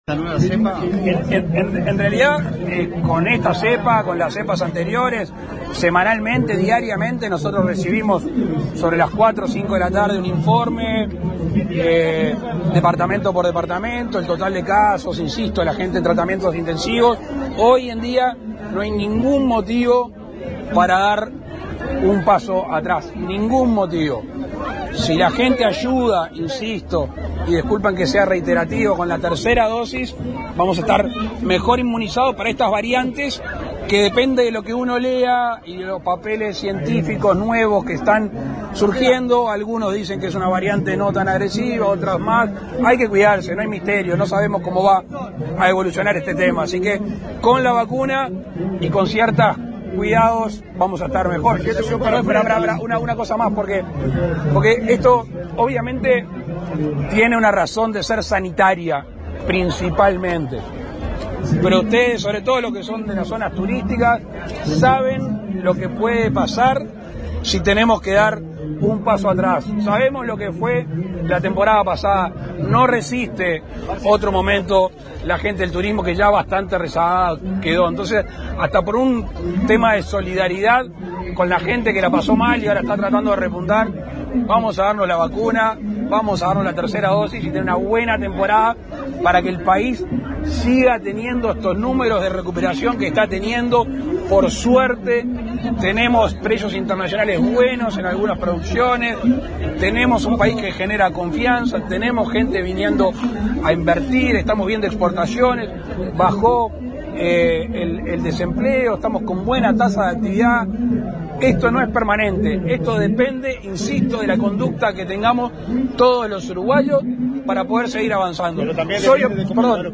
El mandatario participó en la tradicional Paella en Piriápolis que da inicio a la temporada estival y fue el encargado de encender las hornallas para la cocción.